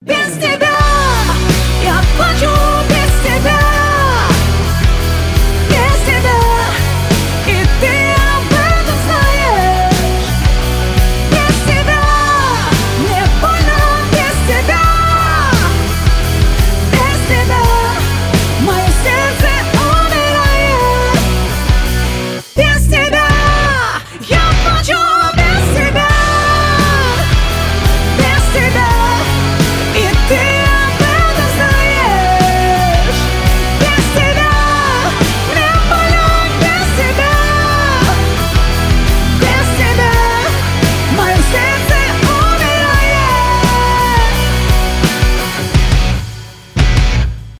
громкие , поп
pop rock